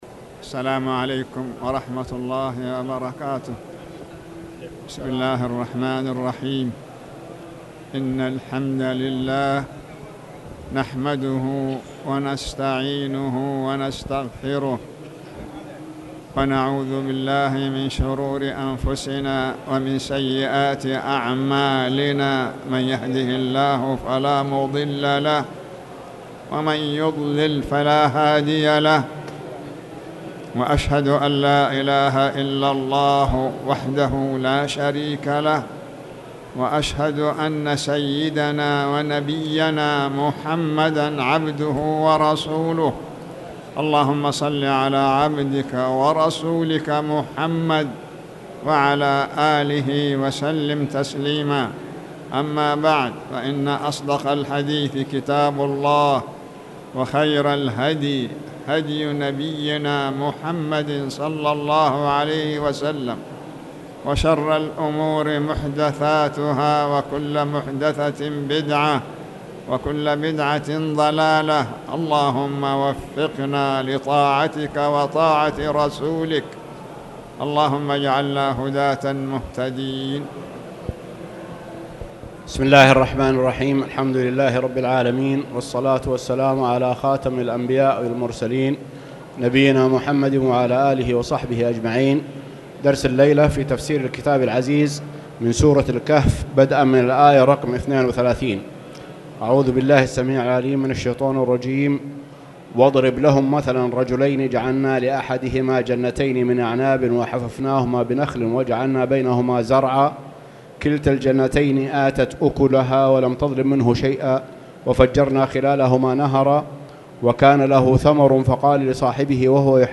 تاريخ النشر ٤ محرم ١٤٣٨ هـ المكان: المسجد الحرام الشيخ